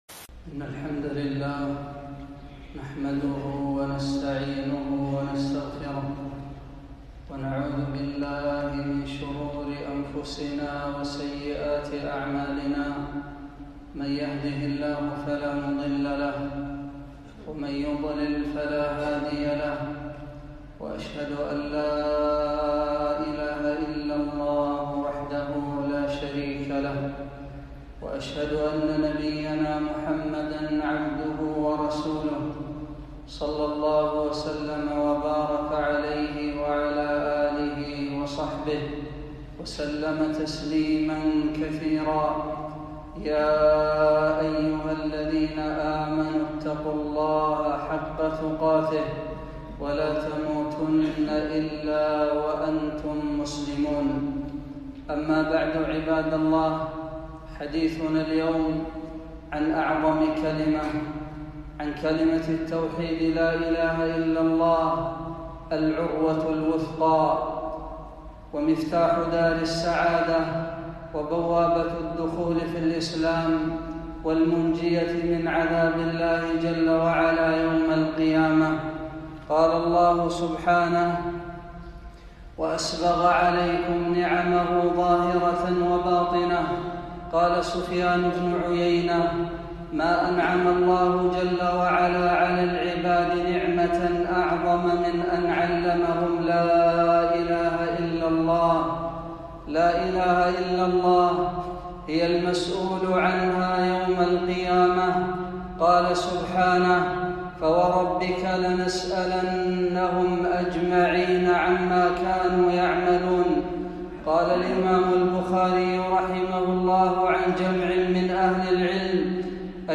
خطبة - بعض فضائل كلمة التوحيد لا إله إلا الله